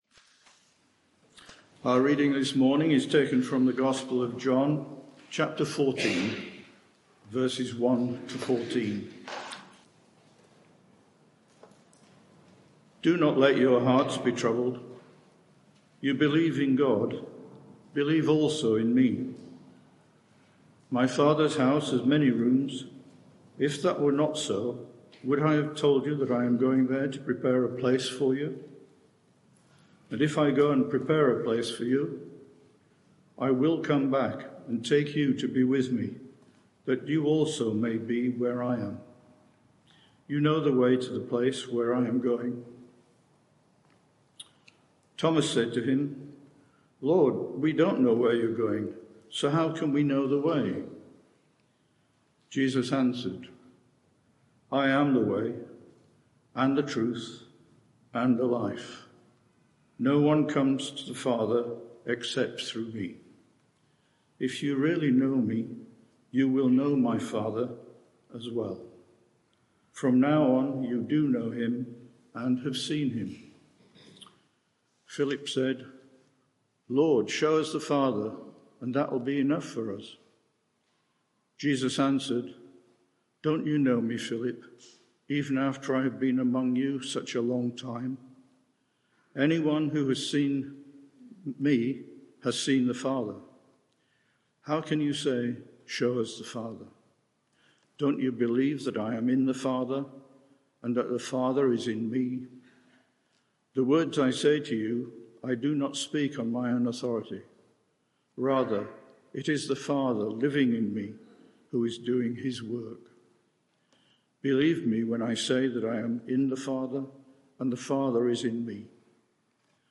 Media for 11am Service on Sun 25th Aug 2024 11:00 Speaker
I AM Theme: I am the way, the truth and the life Sermon (audio)